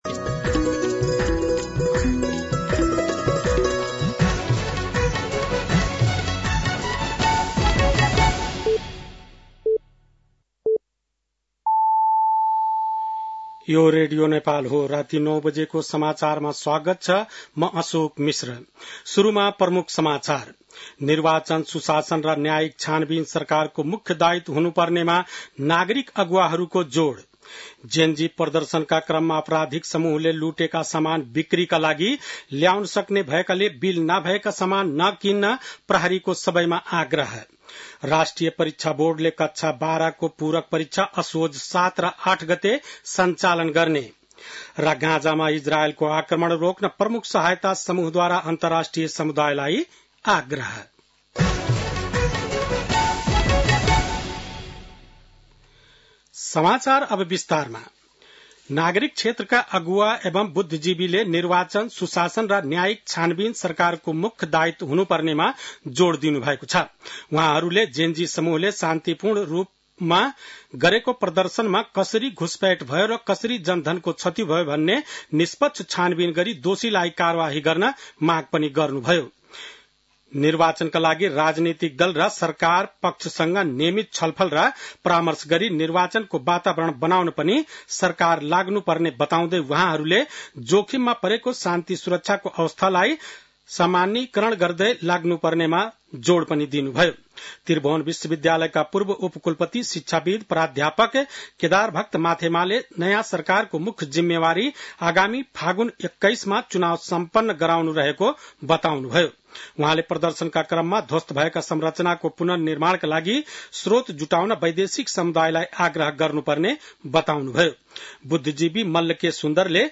बेलुकी ९ बजेको नेपाली समाचार : १ असोज , २०८२
9-pm-nepali-news-6-01.mp3